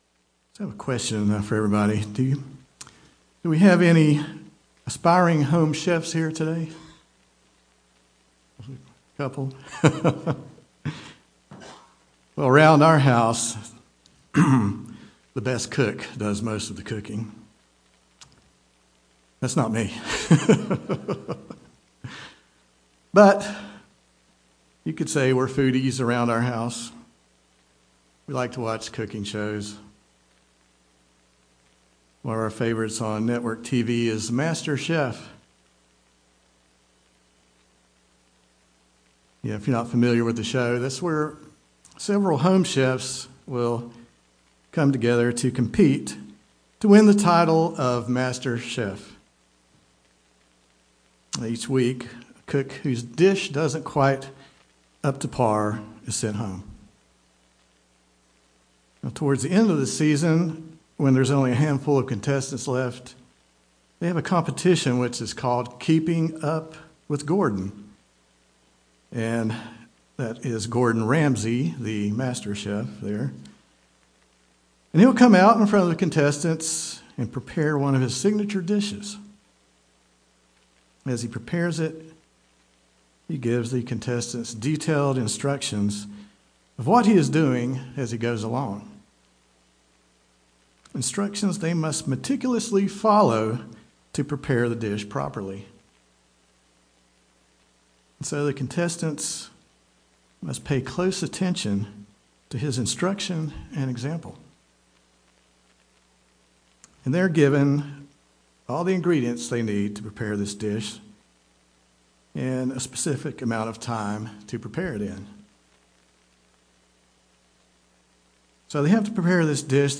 Given in Greensboro, NC
In this message we look to answer this question and discuss ways that we can grow as a disciple of Christ and become a true follower of Him UCG Sermon Studying the bible?